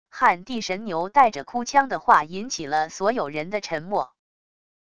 撼地神牛带着哭腔的话引起了所有人的沉默wav音频